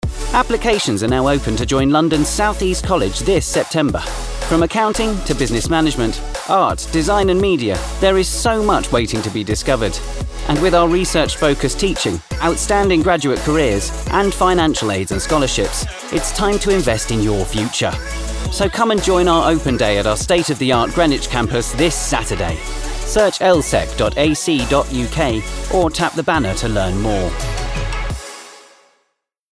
Voice Reel
London South-East College Commercial - Contemporary, Bright, Positive